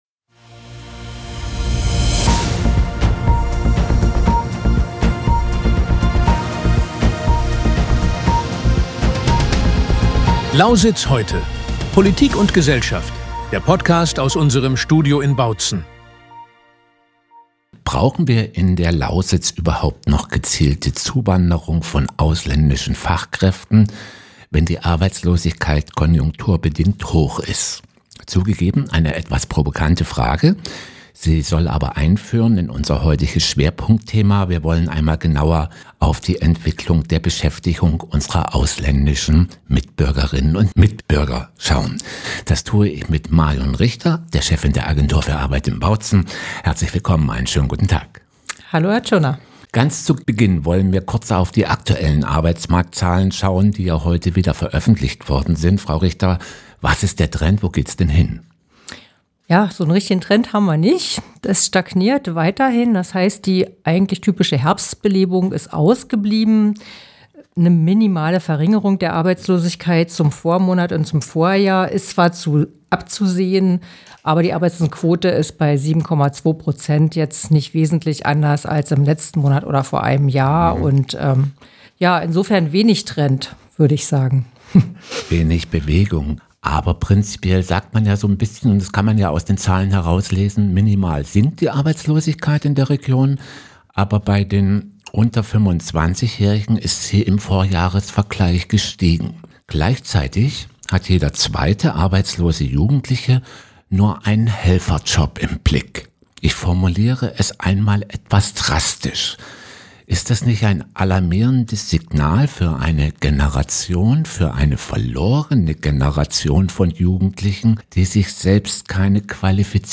Ein zahlenbasiertes Gespräch, das mit Vorurteilen aufräumt und die wahren Herausforderungen für den Lausitzer Arbeitsmarkt benennt.